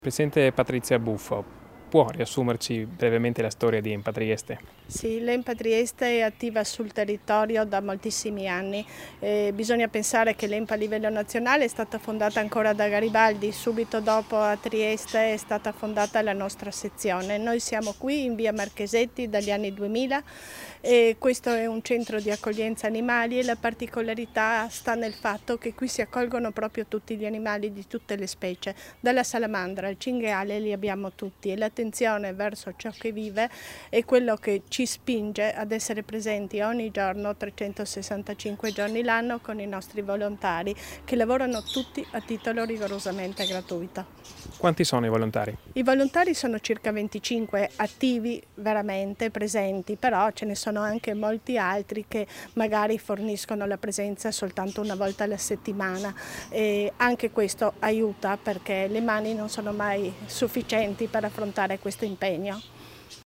a margine della visita di Debora Serracchiani all'Ente Nazionale Protezione Animali (ENPA), rilasciate a Trieste il 3 luglio 2017